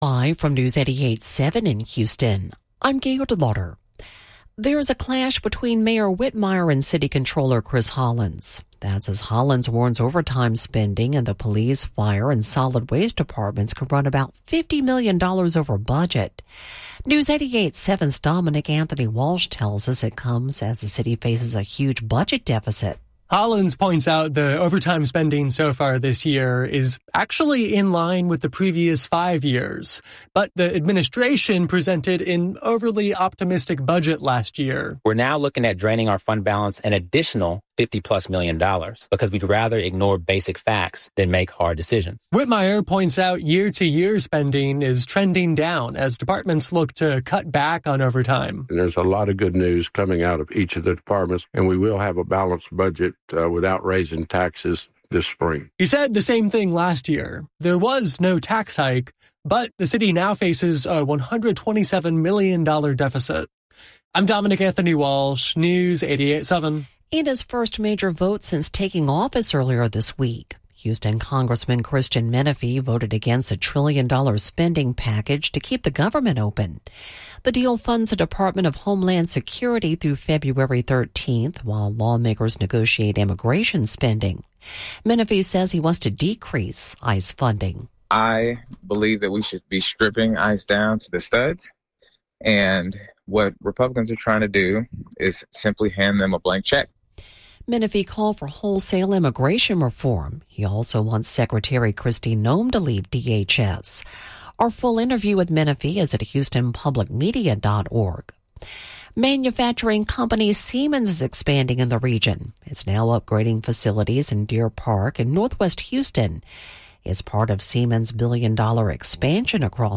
Local newscasts from Houston Public Media, updated during drive times.
Genres: Daily News, News